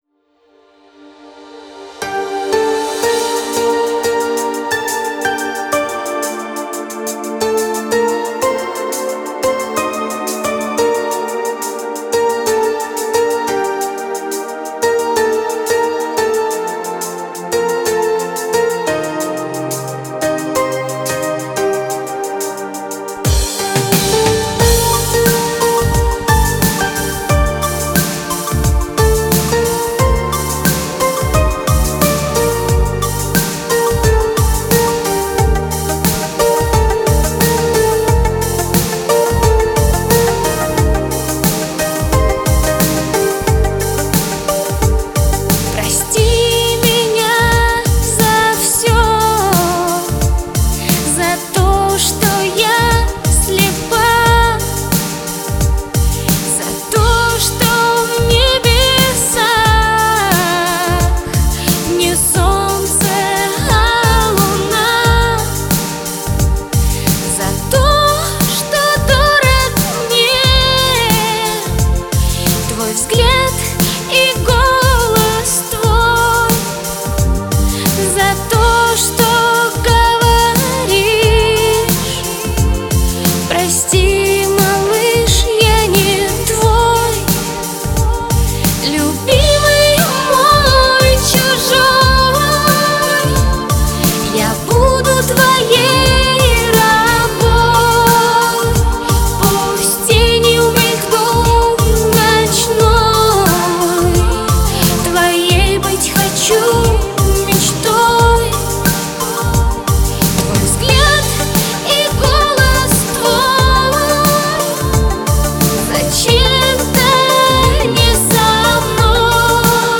но такая душевная и грустная ..